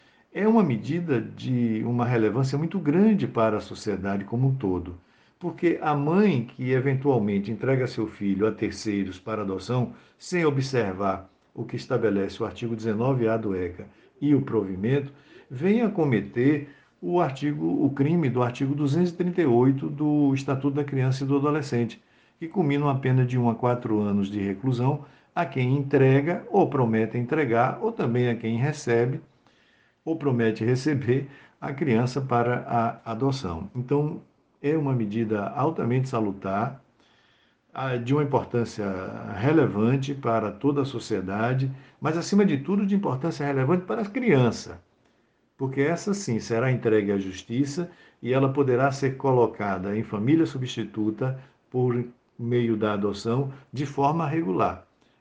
O Desembargador Salomão Resedá, responsável pela Coordenadoria da Infância e Juventude (CIJ) do PJBA, ressalta a importância do Provimento e como isso influencia na vida das crianças: